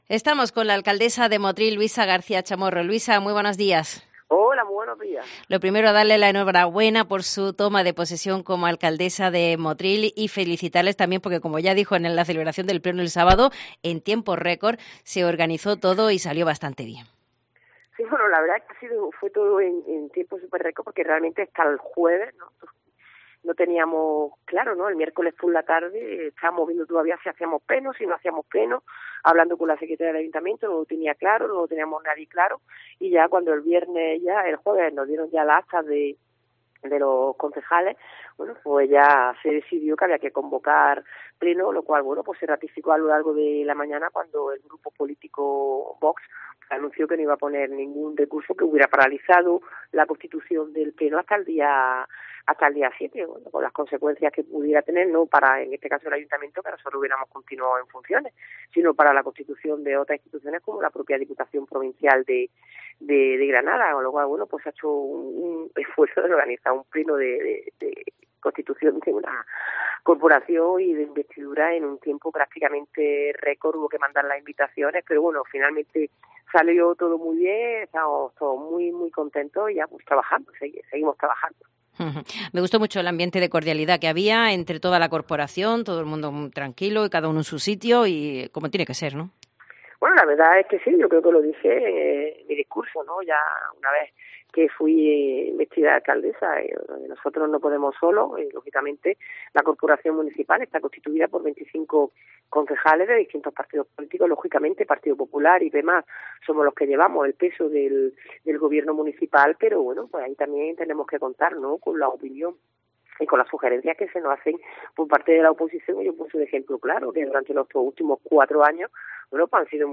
Con respecto a la presidencia de la Mancomunidad "hay que ser generosos" nos dice la alcaldesa de Motril